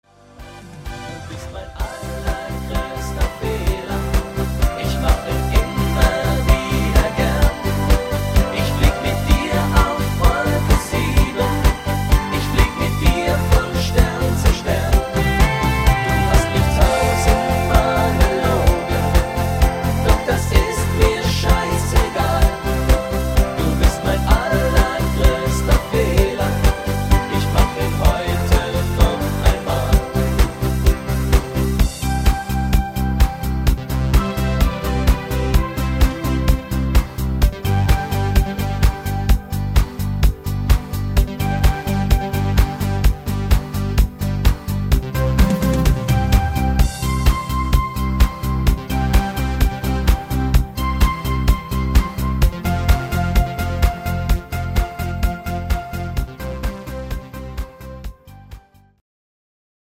Männer Version